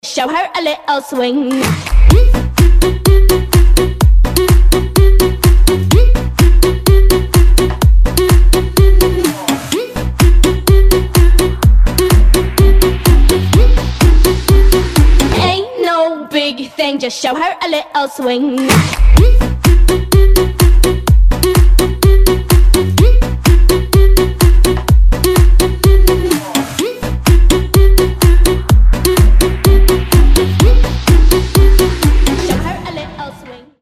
из Клубные